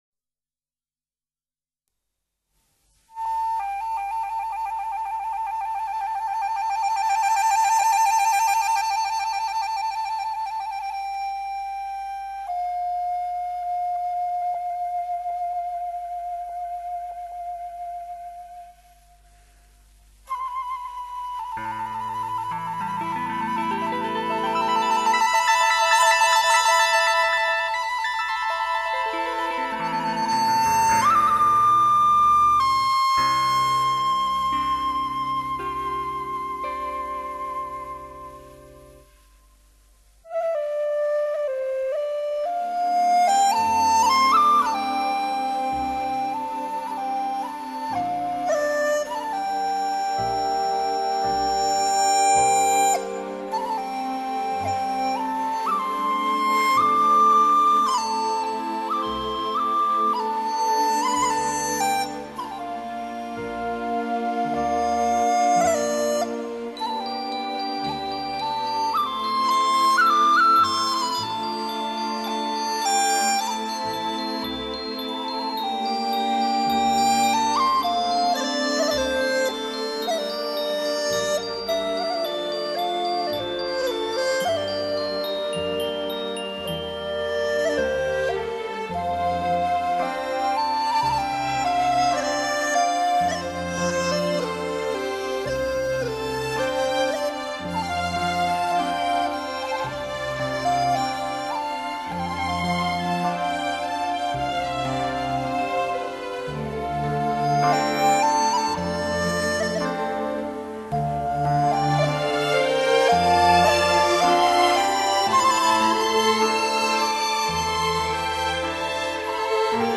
曲子的引子部分是由四个极其简单的长音音符组成。
在音符越少的地方，它的速度越慢，时值越长。在音符越密集的地方，它的速度越快，时值越短，有时甚至是一带而过。
快板是全曲的高潮部分，慢板、快板之间用一段渐快衔接，进入快板之后，中间出现了连续十六分音符的长句。整个快板为平稳进行，没有音符之间的大跳。
快板渐慢后全曲落在“角”音的长音上，并且运用了和引子相同的处理技法，与引子首尾呼应，仿佛那鹧鸪扶摇而去，渐渐消失在人们的视野里。